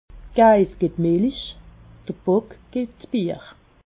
Expressions populaires
Haut Rhin
Ville Prononciation 68
Ribeauvillé